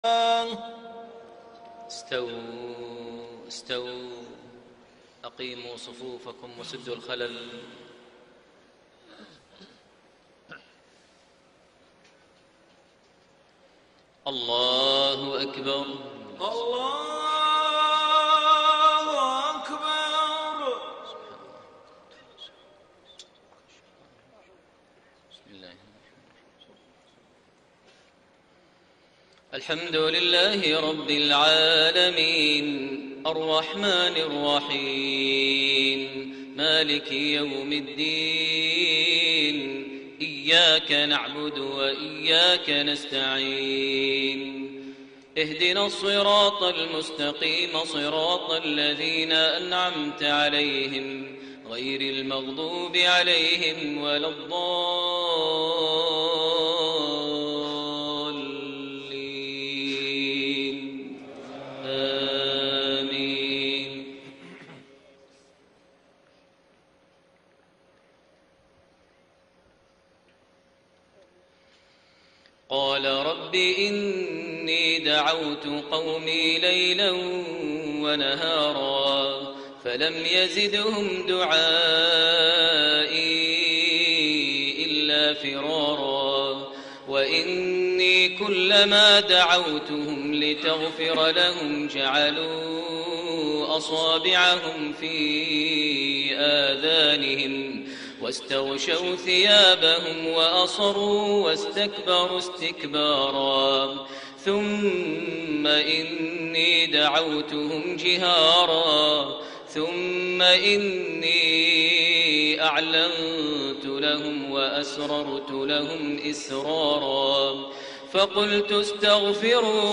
صلاة المغرب 22 جمادى الآخرة 1433هـ سورة نوح 5-25 > 1433 هـ > الفروض - تلاوات ماهر المعيقلي